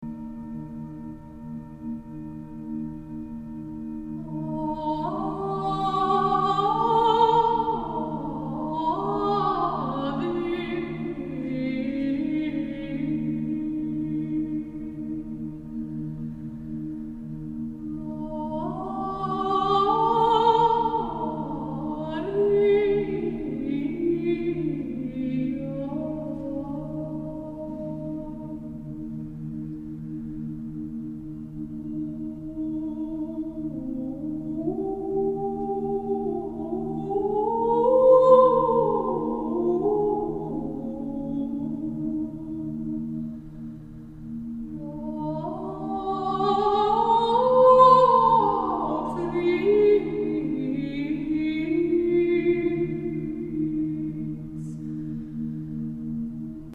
con Coro Armonico y Cuenco Tibetano